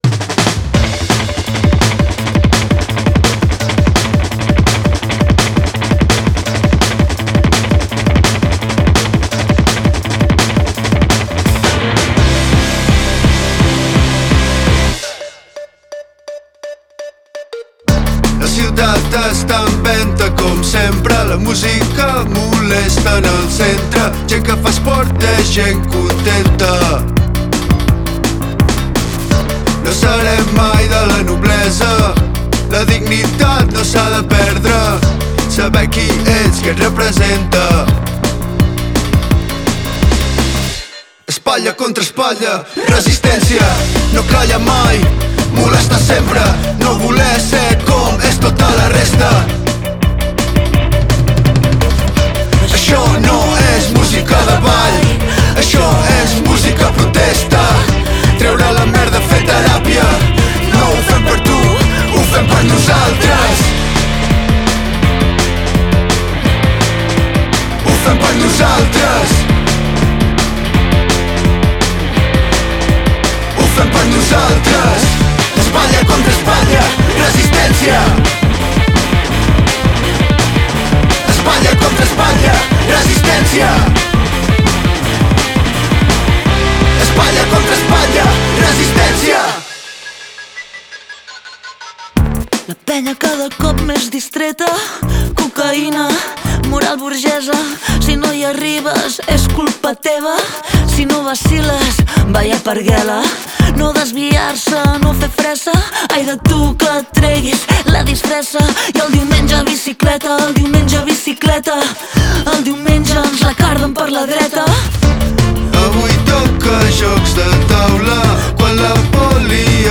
Genres: Indie Rock, Alternative Rock